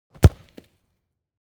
playerGroundHit2.wav